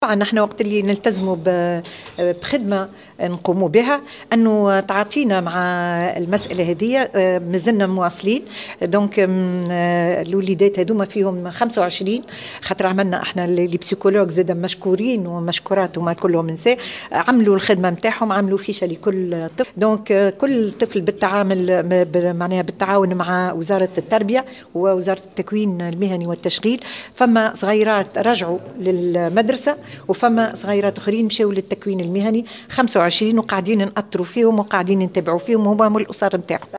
قالت وزيرة المرأة والأسرة والطفولة نزيهة العبيدي في تصريح لمراسلة الجوهرة "اف ام" اليوم الخميس إن تعاطي الوزارة مع مسألة أطفال مدرسة الرقاب مازال متواصلا.